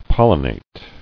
[pol·li·nate]